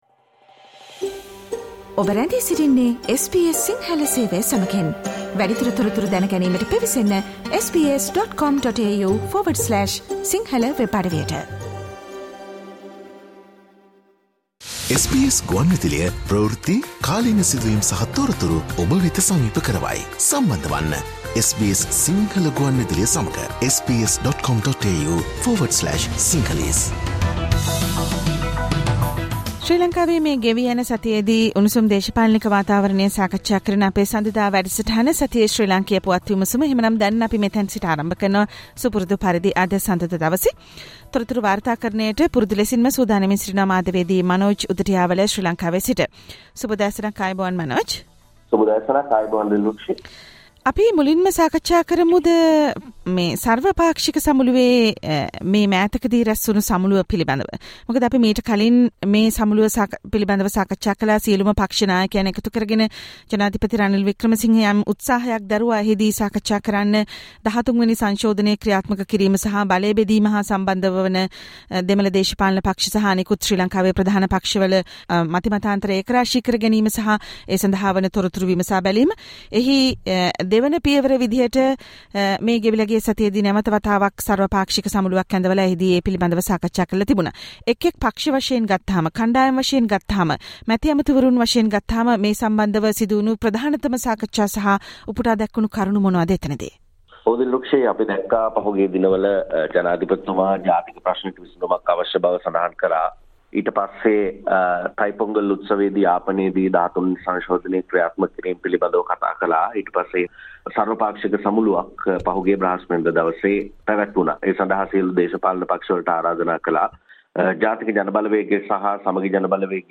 All party leader's meeting in Sri Lanka_ SBS Sinhala_ Sri Lankan news wrap